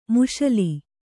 ♪ muśali